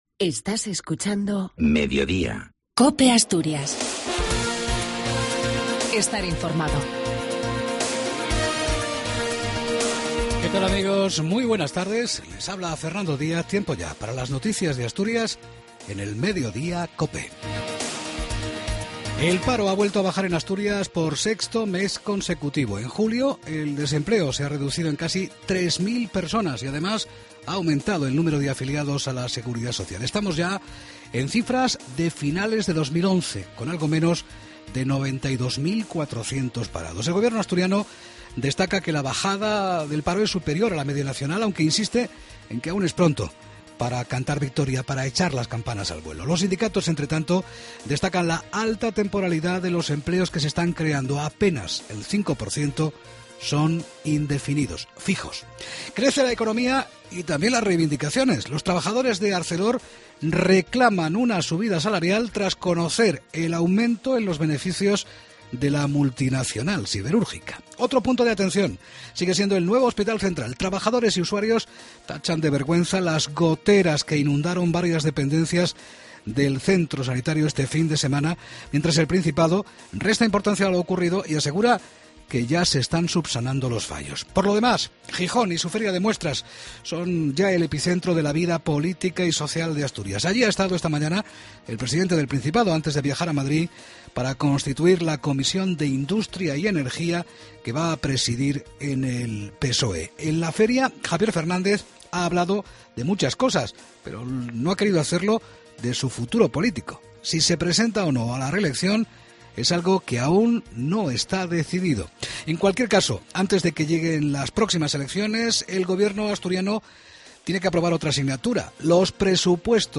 AUDIO: LAS NOTICIAS DE ASTURIAS Y OVIEDO AL MEDIODIA.